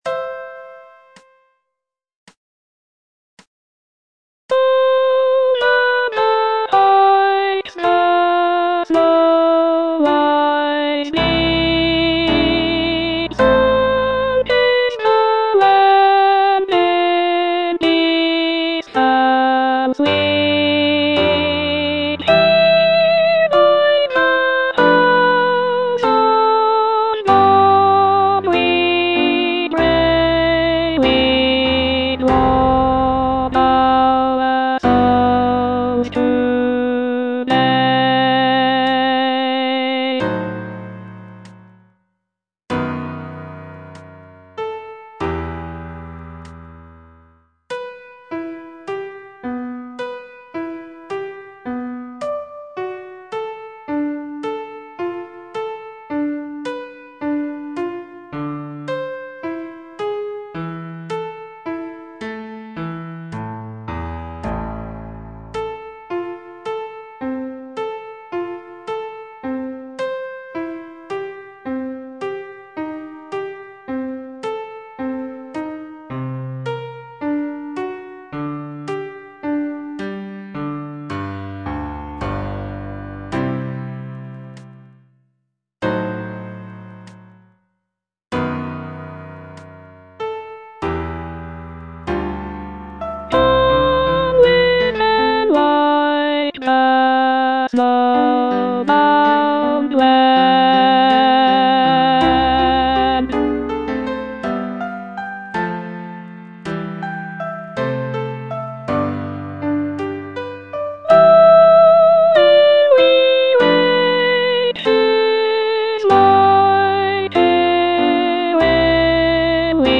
E. ELGAR - FROM THE BAVARIAN HIGHLANDS Aspiration (alto II) (Voice with metronome) Ads stop: auto-stop